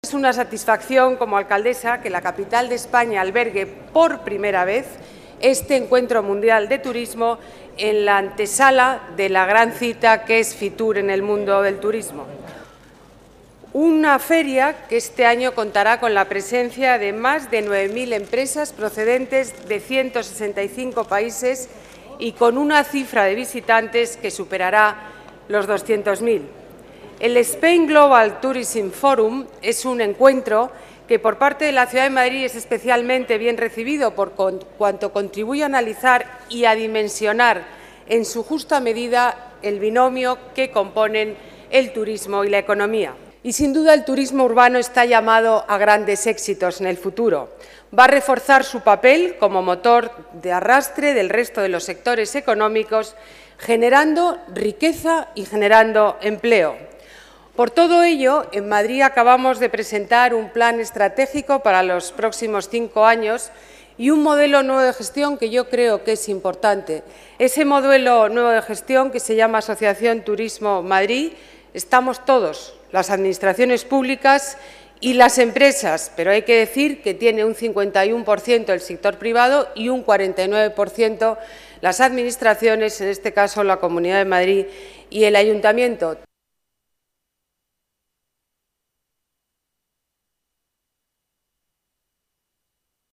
Ana Botella asiste al Spain Global Turism Forum en Madrid
Nueva ventana:Declaraciones de Ana Botella en cita con Spain Turism Forum Global Madrid